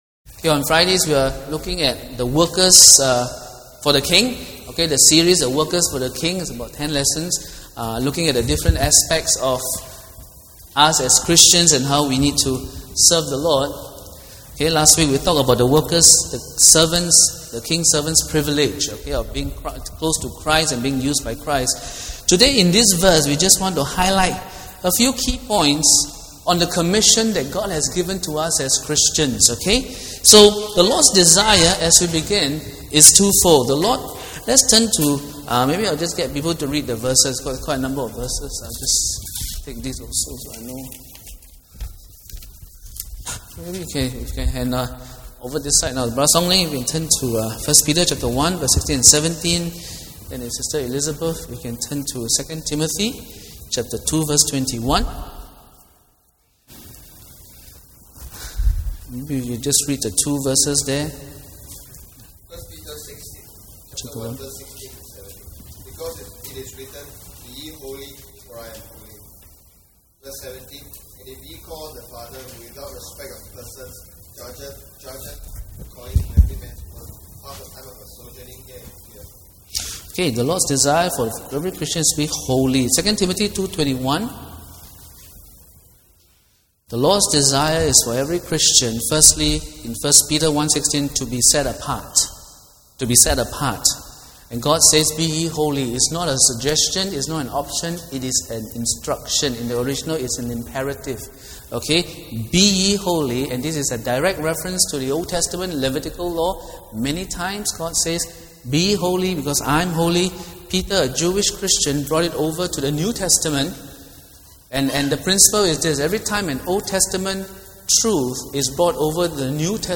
The sermon title used presently is a working title.